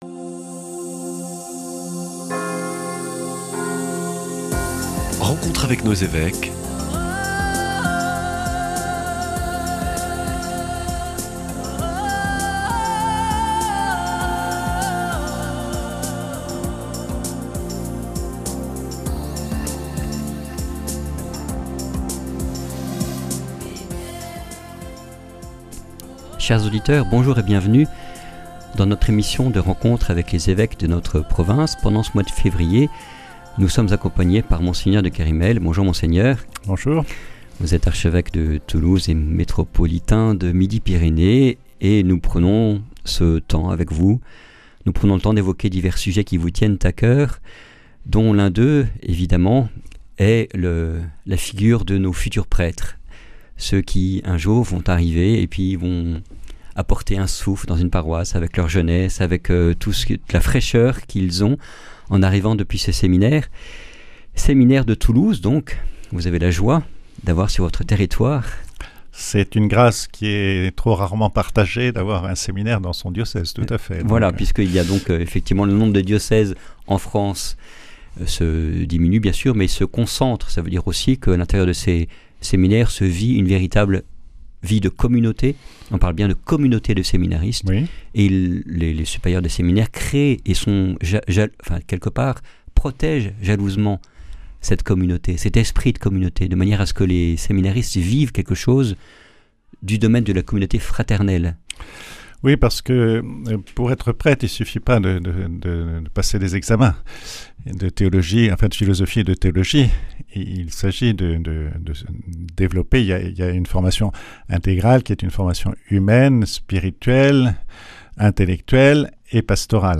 Rencontre avec Mgr de Kerimel